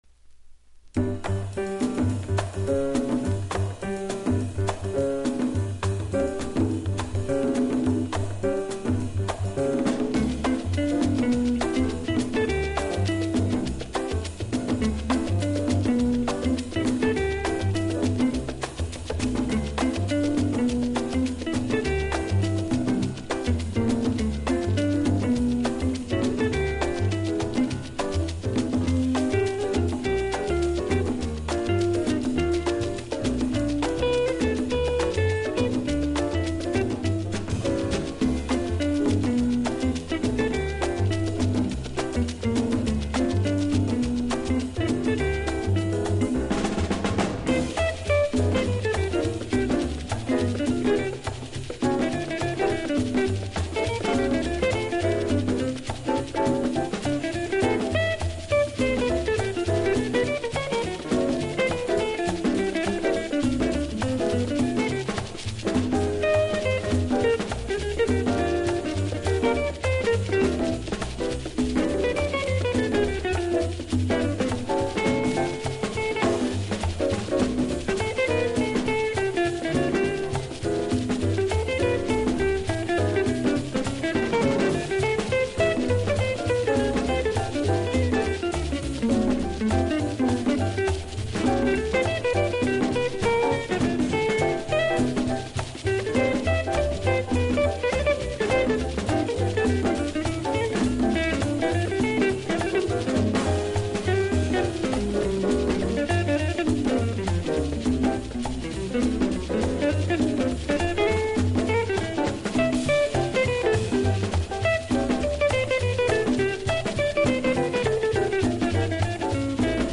コンガ